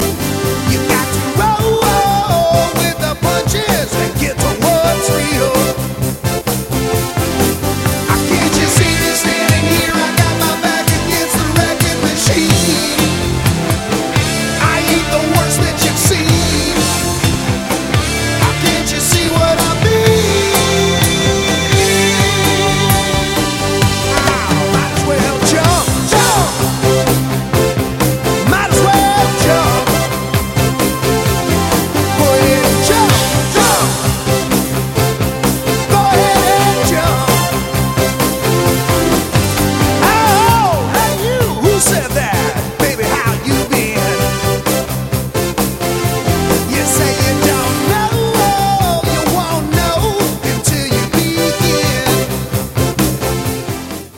OLD SCHOOL ROCK no. 3